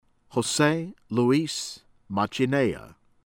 LAMPREIA, LUIZ FELIPE loo-EESS     fay-LEE-pay    lahm-PRAY-ah